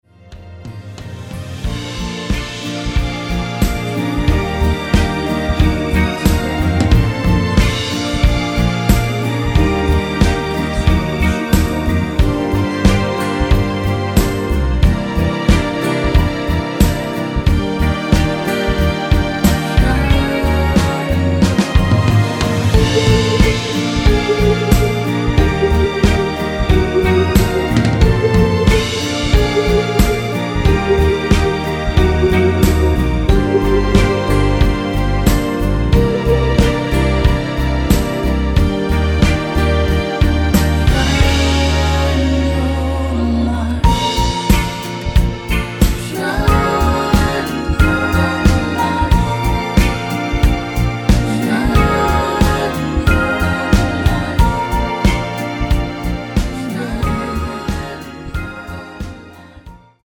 원키 코러스 포함된 MR 입니다.(미리듣기 참조)
Bb
앞부분30초, 뒷부분30초씩 편집해서 올려 드리고 있습니다.